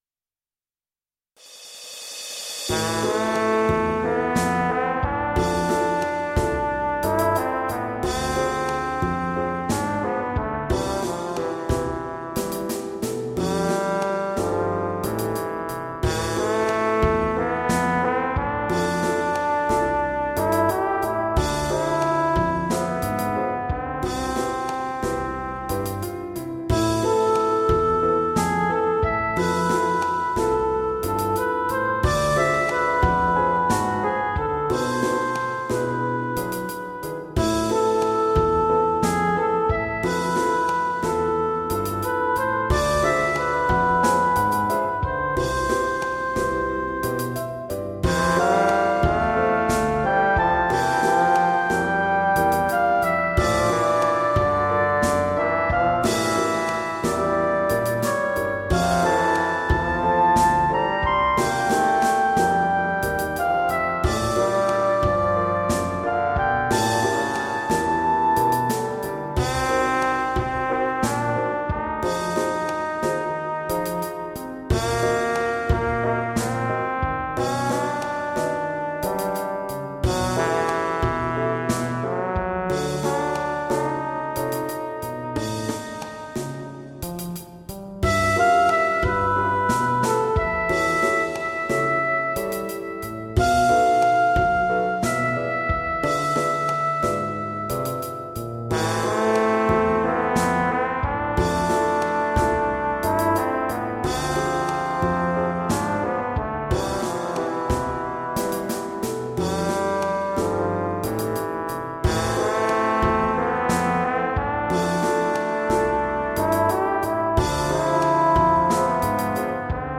ポップス 　ＭＩＤＩ(36KB） 　YouTube
ＭＰ３(2.3MB） 少し物憂げですが春の風景を喜んでいます。